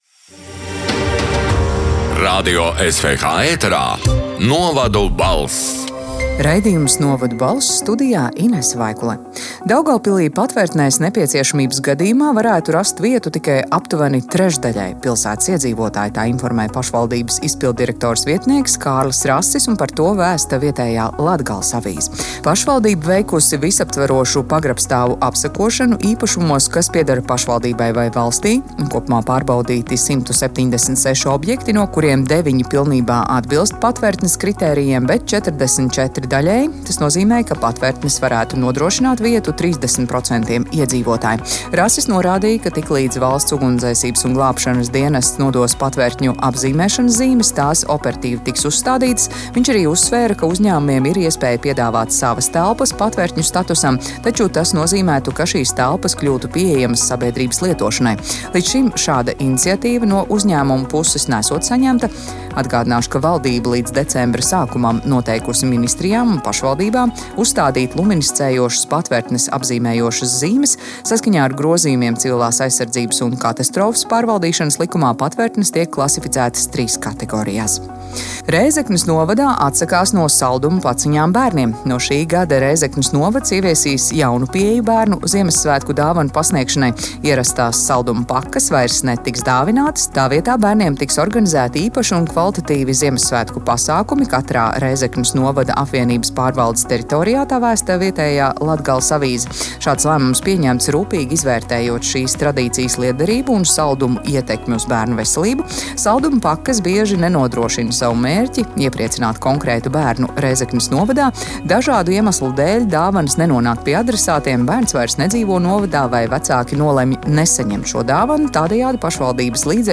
“Novadu balss” 29. novembra ziņu raidījuma ieraksts: